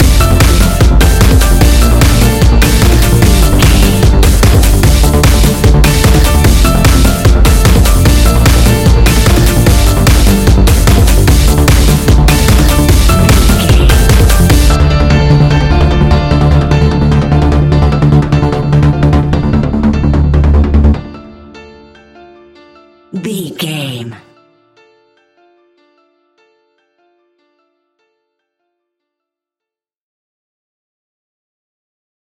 Aeolian/Minor
D
Fast
heavy
energetic
uplifting
hypnotic
drum machine
piano
synthesiser
percussion
acid house
electronic
uptempo
synth leads
synth bass